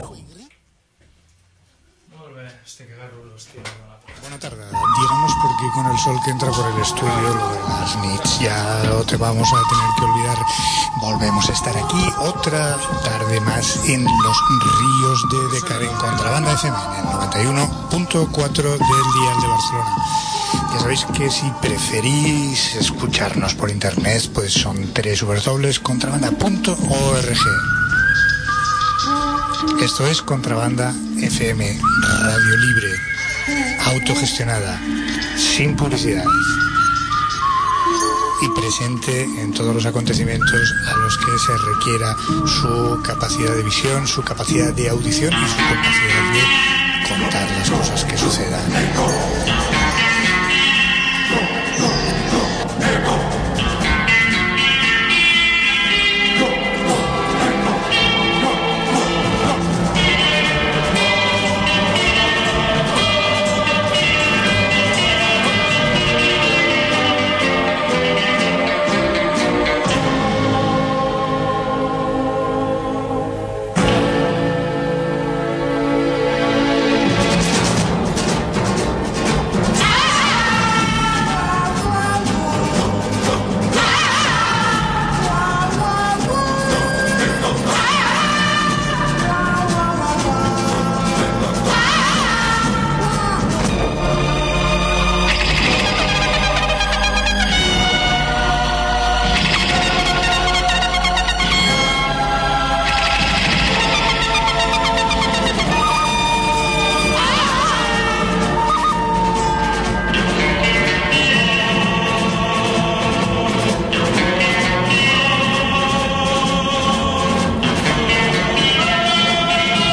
Minvats en efectius i en reflexes per afrontar limitacions varies, els majares compten amb la visita de membres de la Plataforma Defensem el Park Güell, que venen a denunciar el projecte municipal de limitar l’accès a aquest emblemàtic parc barceloní i a invitar a la gent a que participi en la proposta d’alternatives al tancament.